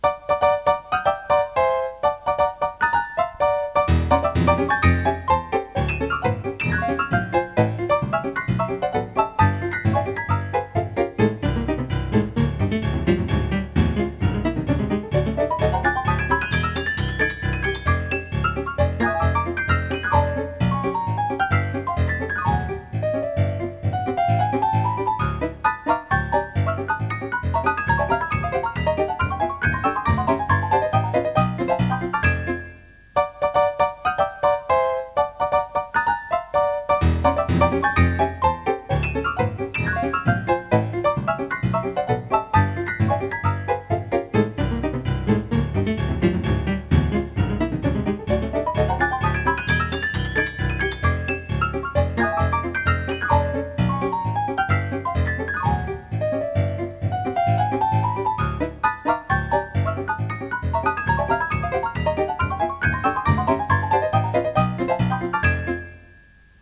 De 1993 à 2002, j'ai utilisé mon piano MIDI pour un grand nombre d'expériences musicales ou sonores, totalisant une vingtaine d'heures sur cassettes audio.
D'abord mon tout premier enregistrement de 1993, juste après l'achat de ce piano : la « Danse du dingue ». C'est une simple improvisation, en laissant mes mains frapper au hasard sur le clavier, mais je continue à trouver qu'elle a une sacrée pêche — et je n'ai plus jamais fait aussi bien par la suite !